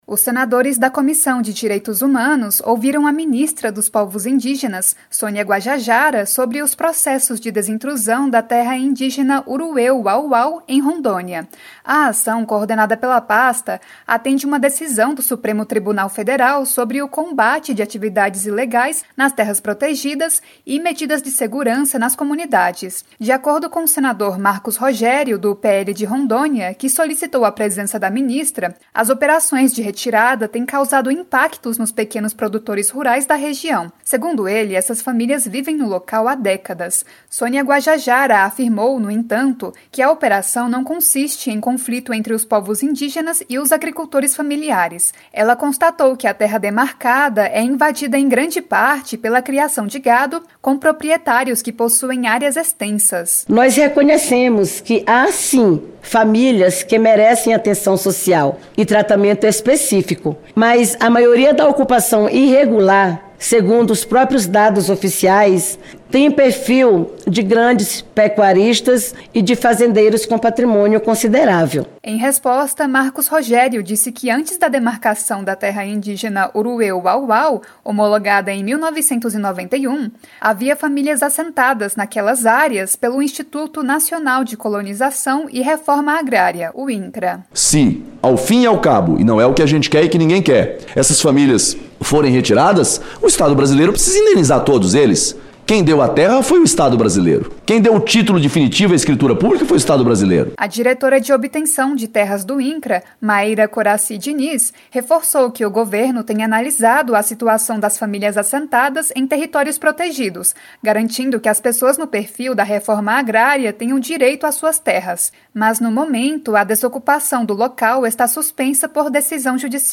Audiência Pública
A Comissão de Direitos Humanos (CDH) debateu em audiência pública, nesta quarta-feira (10), os processos de desintrusão da Terra Indígena Uru-Eu-Wau-Wau, em Rondônia.